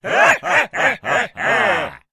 Хохот ватаги пиратов